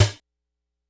snr_40.wav